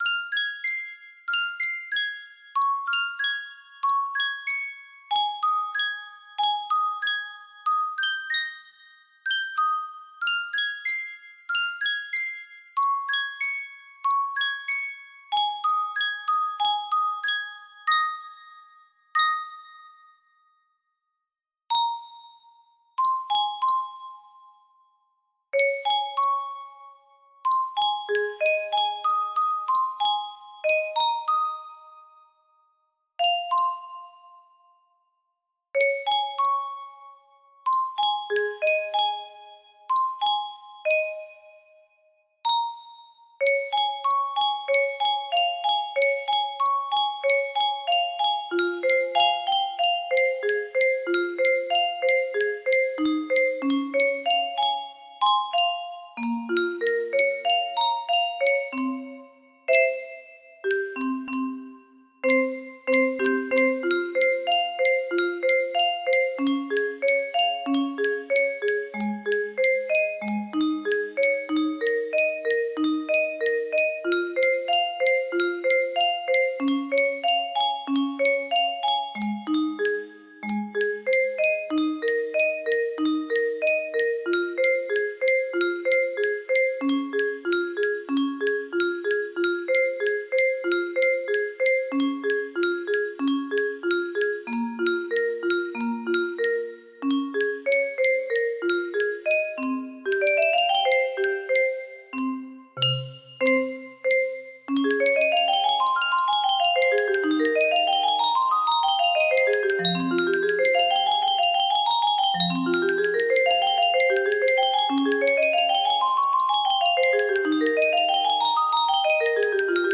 test music box melody
Full range 60